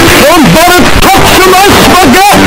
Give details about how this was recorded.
Somebody Touched My Spaghetti Earrape